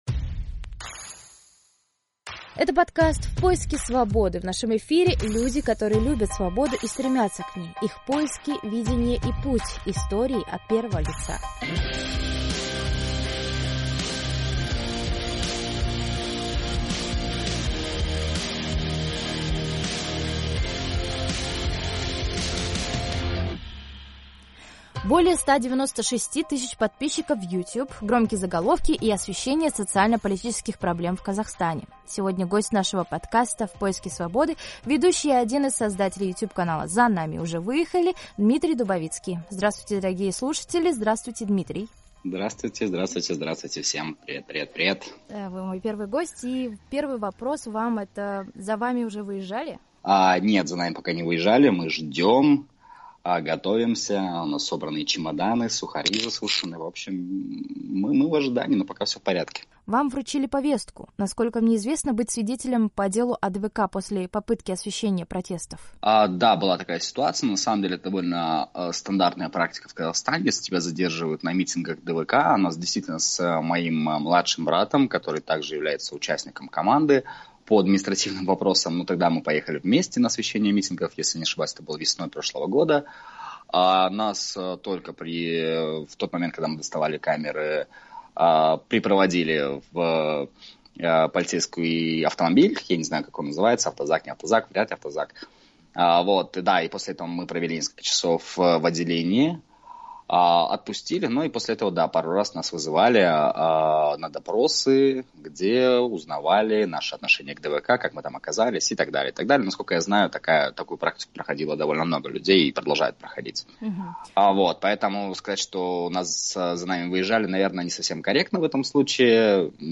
Разговор с YouTube-блогером